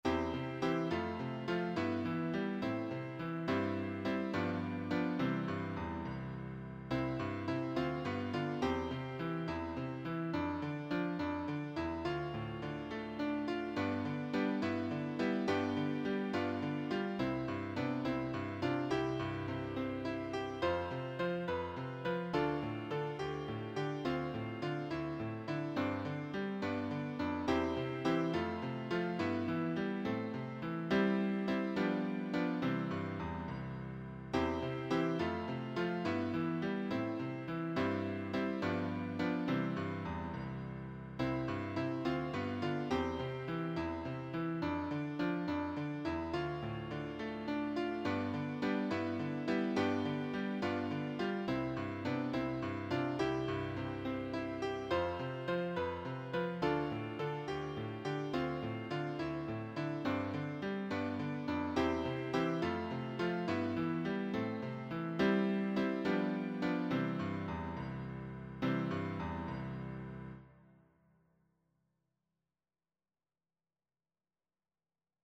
Bring me to the Saviour (Piano Accompaniment)
Bring-me-to-the-Saviourpianoacc.mp3